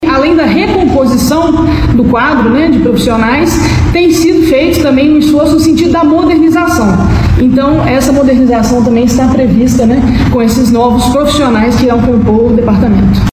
Diretora-geral do Demlurb, Ana Luisa Guimarães ressaltou os avanços que o concurso trará para o Departamento.